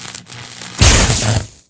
stungun.ogg